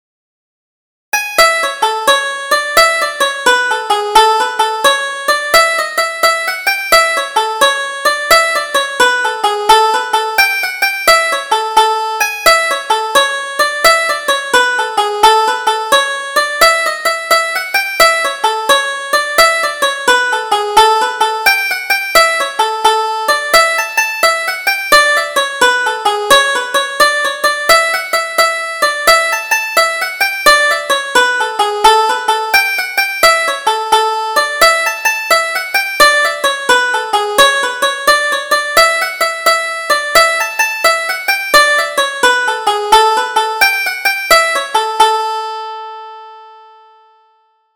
Double Jig: Condon's Frolics